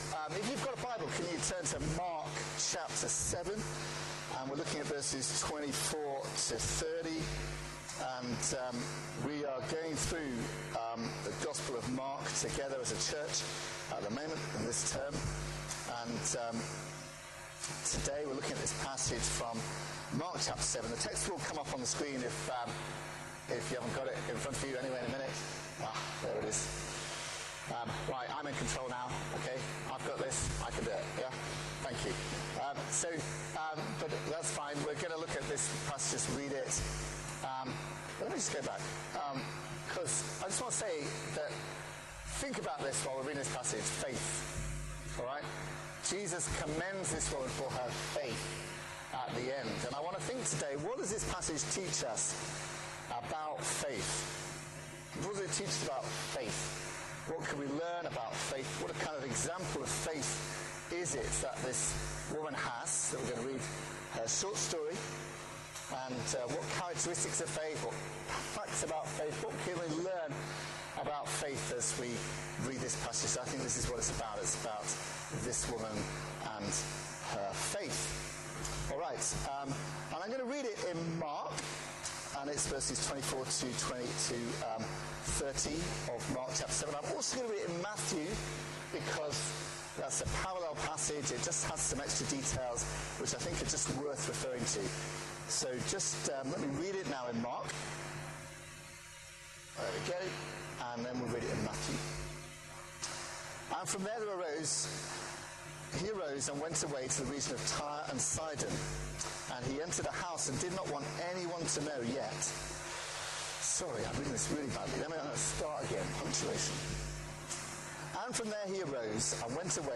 Download Faith That Crosses Divides | Sermons at Trinity Church